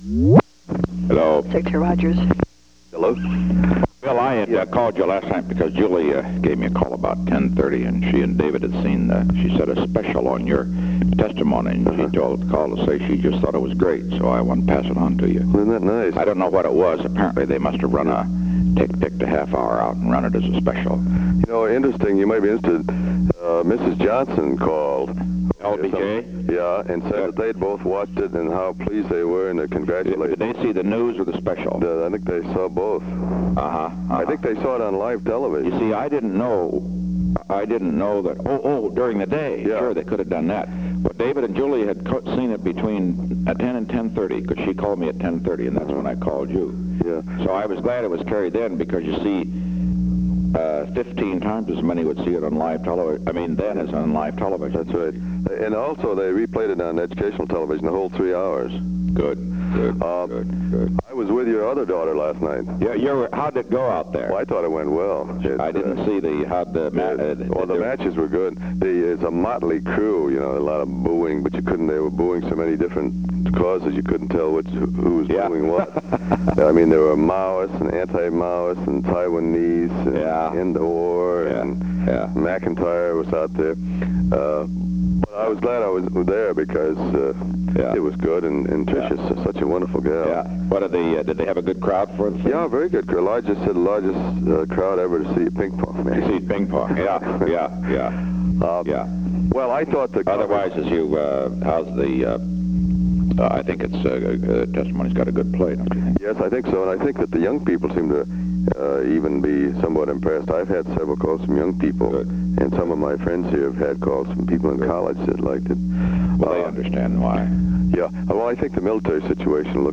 On April 18, 1972, President Richard M. Nixon and William P. Rogers talked on the telephone from 9:20 am to 9:23 am. The White House Telephone taping system captured this recording, which is known as Conversation 023-014 of the White House Tapes.
Location: White House Telephone